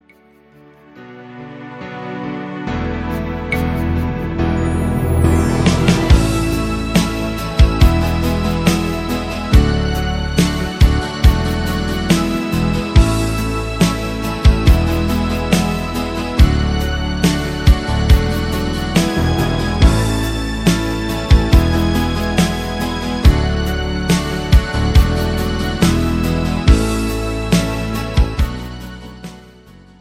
Midi Demo